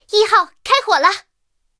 I号夜战攻击语音.OGG